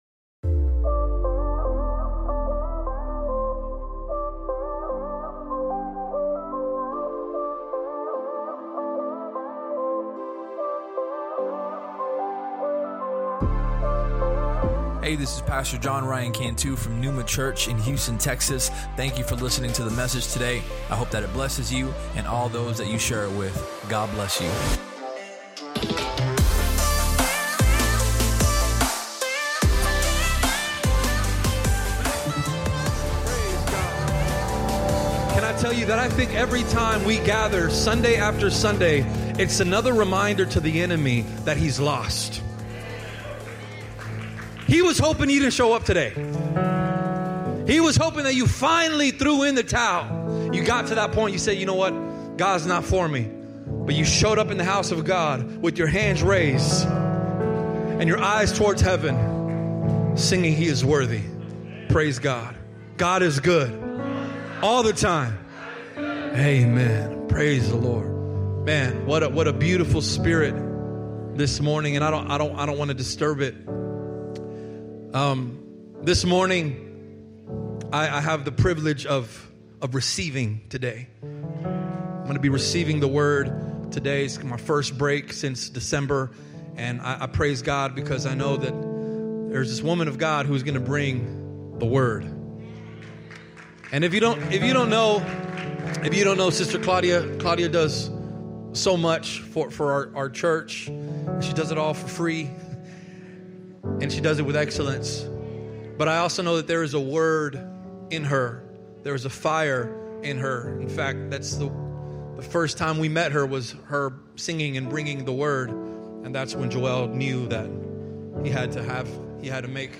Sermon Topics: Prayer, Faithfulness, Legacy If you enjoyed the podcast, please subscribe and share it with your friends on social media.